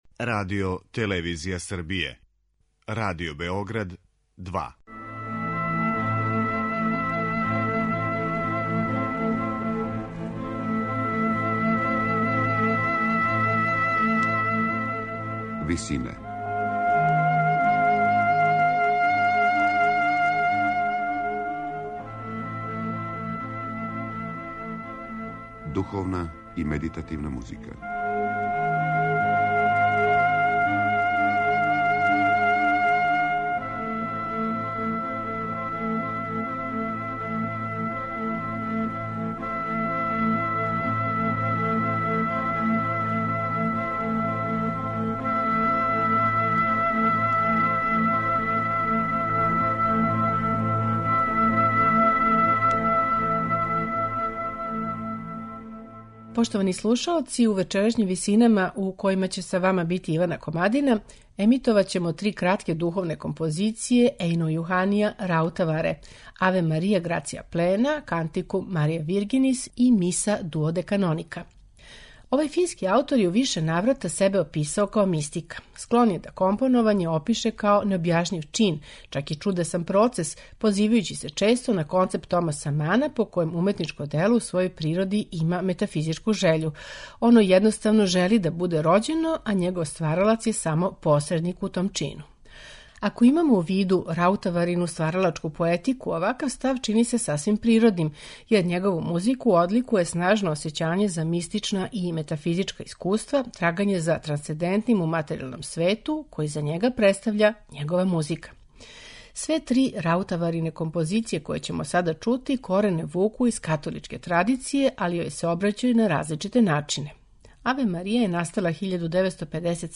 духовне композиције